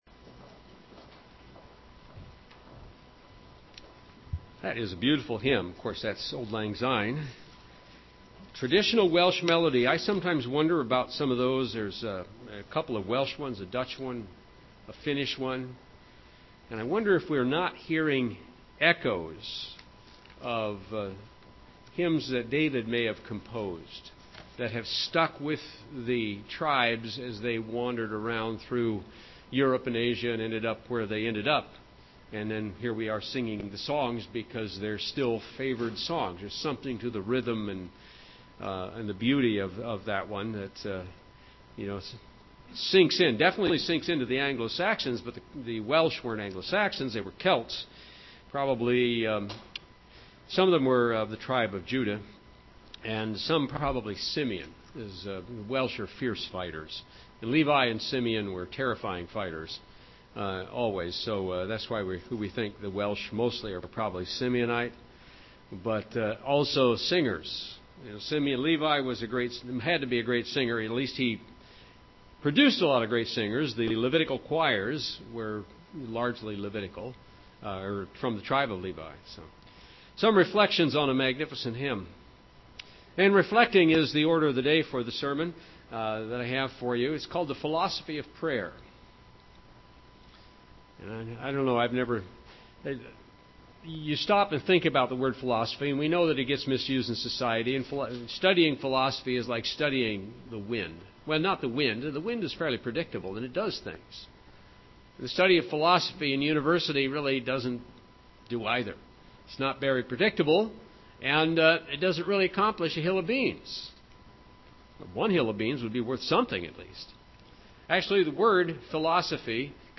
Philosophy means 'The love of wisdom'. This sermon is The Love of Wisdom of Prayer.